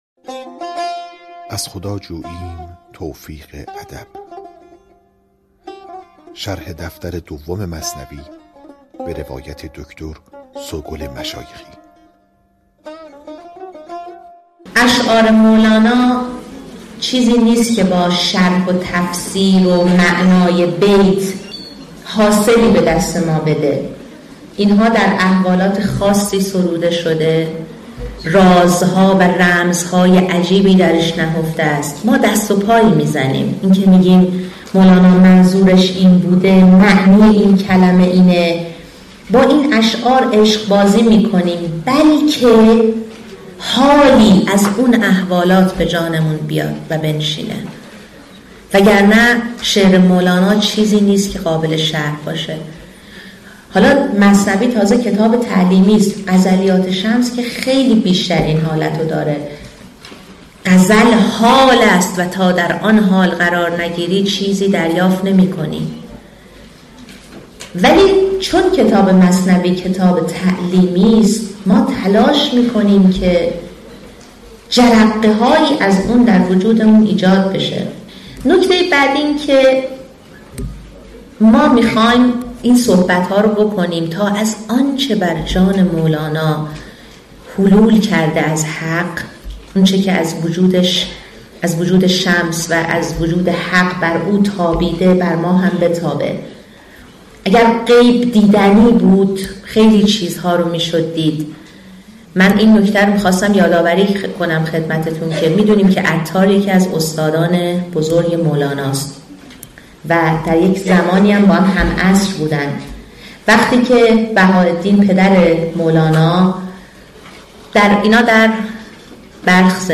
کد محصول : 2112 دانلود فایل صوتی نمونه تدریس شرح مثنوی معنوی دفتر دوم 4,000,000 تومان افزودن به سبد